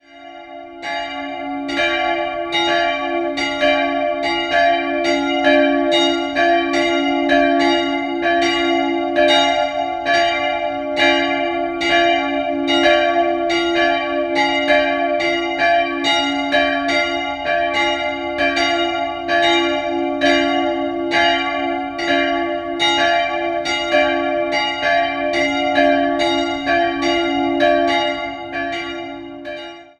Jahrhunderts. 2-stimmiges Kleine-Terz: d''-f'' Die beiden Eisenhartgussglocken wurden 1921 von der Firma Ulrich&Weule in Apolda-Bockenem gegossen.